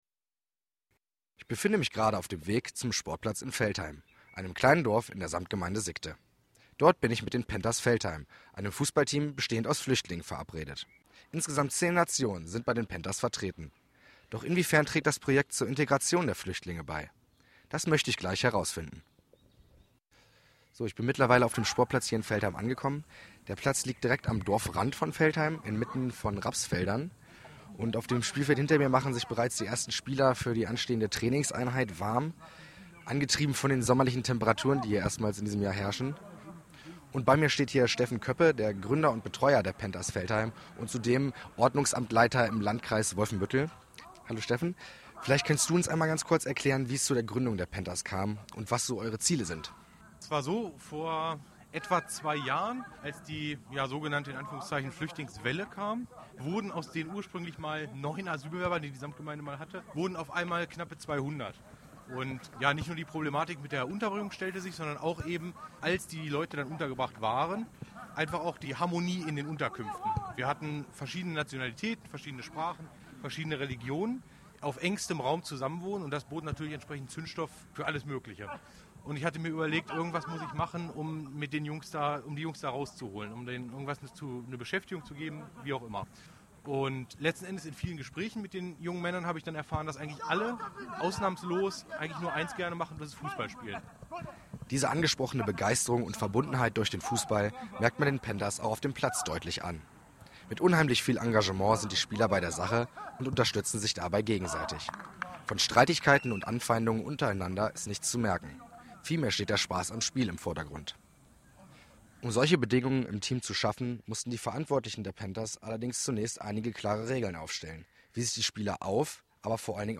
zu Gast auf dem Traininsplatz der „ Veltheim Panthers“.